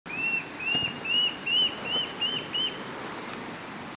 Batará Pintado (Mackenziaena leachii)
Nombre en inglés: Large-tailed Antshrike
Fase de la vida: Adulto
Localidad o área protegida: Parque Provincial Salto Encantado
Condición: Silvestre
Certeza: Vocalización Grabada